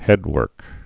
(hĕdwûrk)